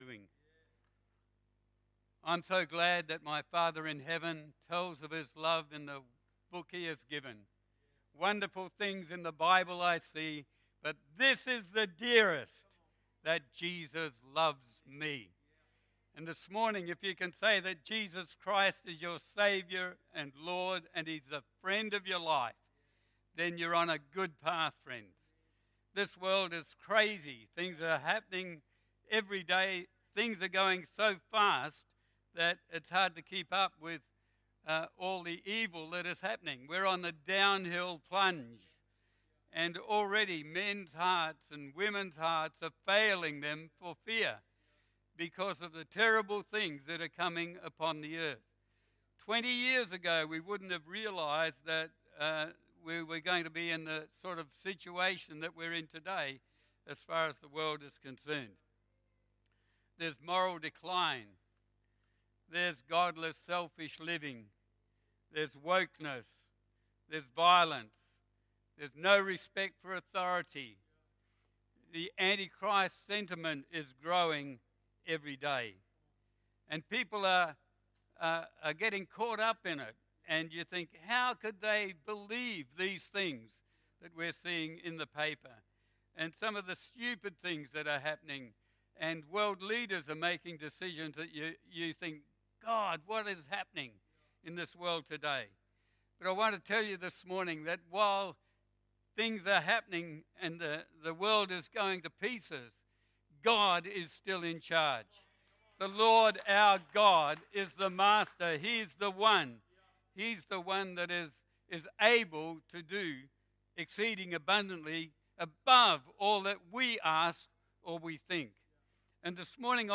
Guest Sermon